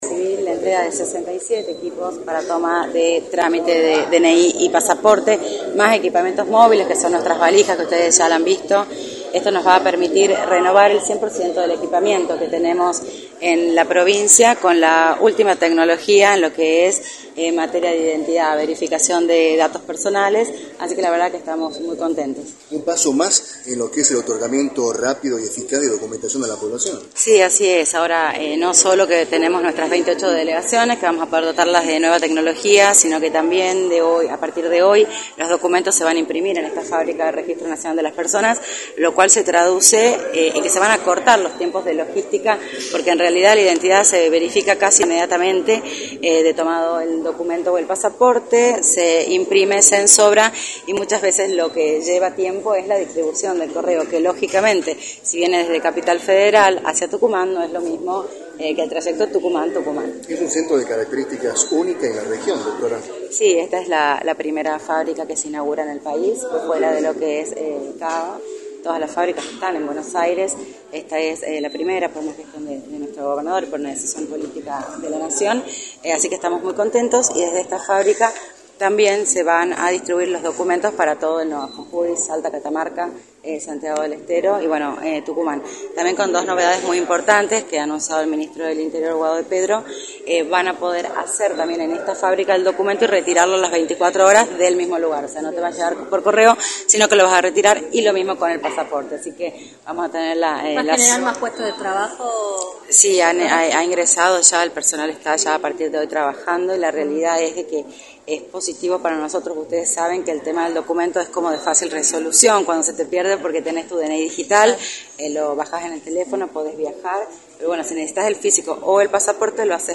“Esta es la primera fábrica que se inaugura fuera de Buenos Aires, aquí también se distribuirán los DNI para Tucumán, Catamarca, Salta y Santiago del Estero, también lo podrán retirar de aquí mismo en 24 horas” señaló Carolina Bidegorry, titular del Registro Civil en la provincia en entrevista para Radio del Plata Tucumán,  por la 93.9.